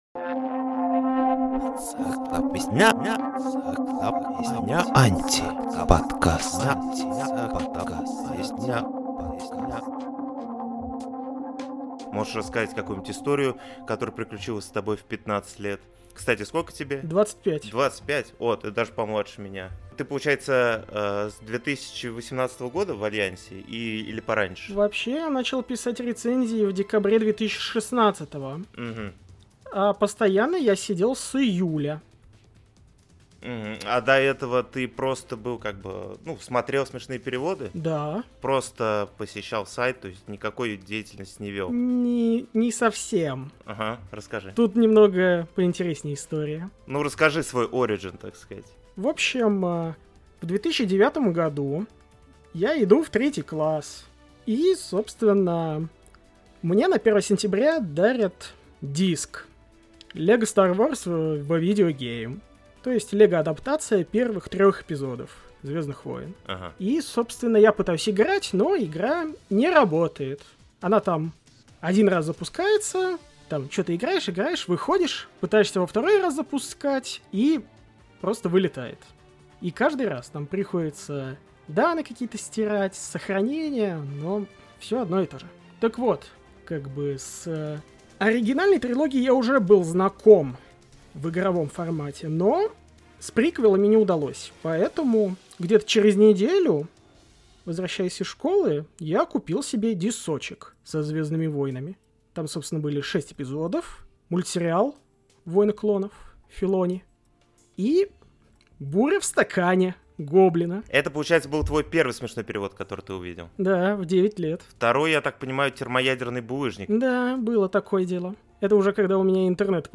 Студия Union Laugh Film представляет четвёртый выпуск своего подкаст-интервью «Антиподкаст».